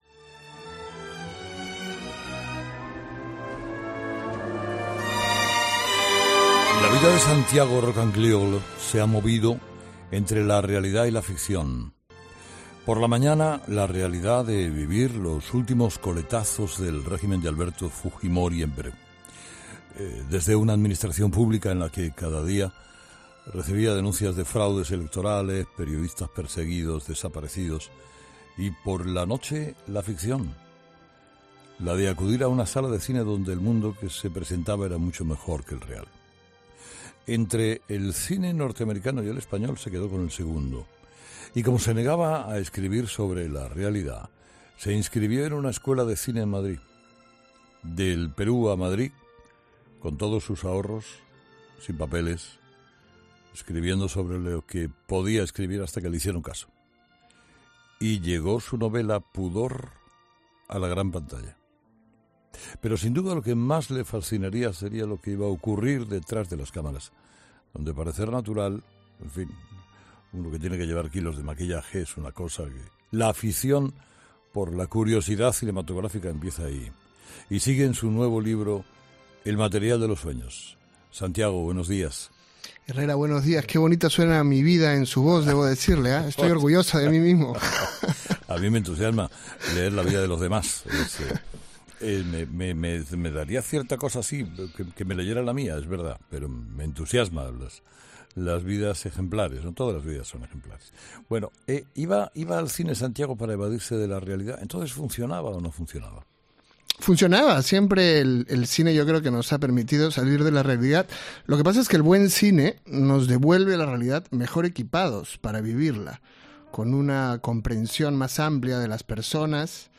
Escucha la entrevista a Santiago Roncagliolo